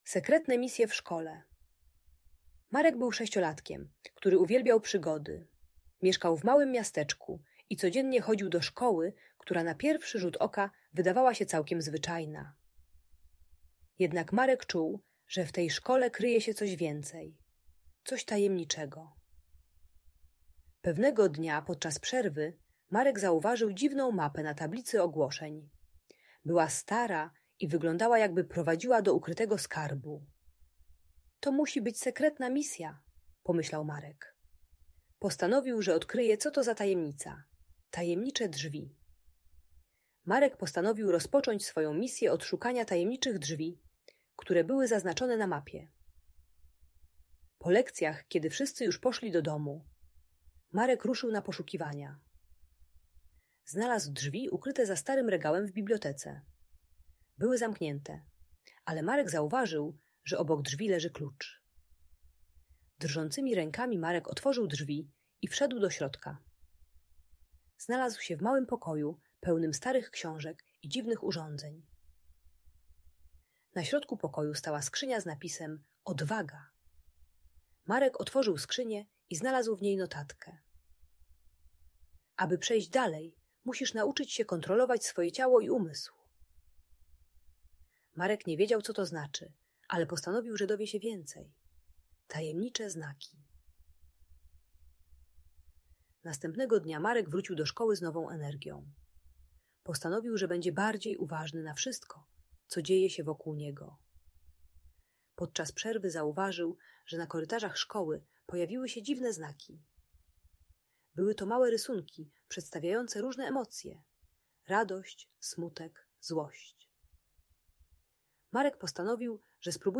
Sekretne Misje w Szkole - Historia o Odwadze i Nauce - Audiobajka